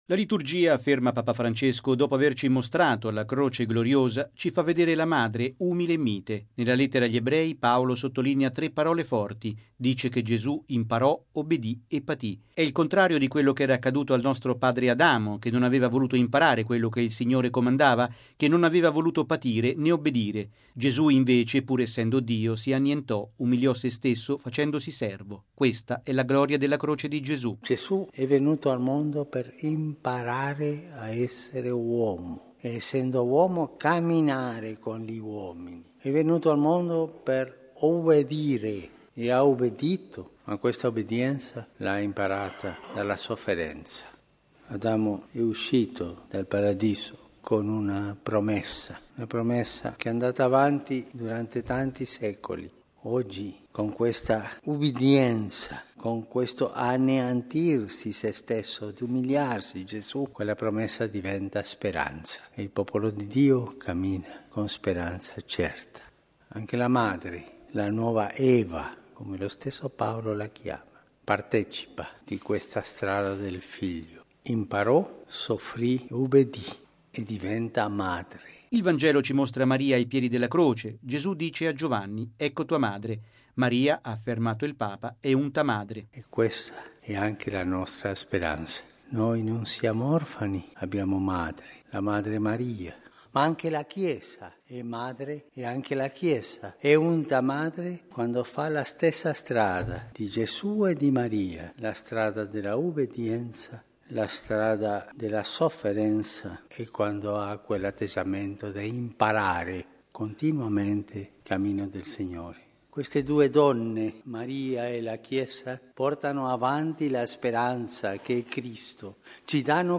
E’ quanto ha detto il Papa presiedendo la Messa mattutina a Santa Marta nel giorno in cui si celebra la memoria della Beata Vergine Addolorata.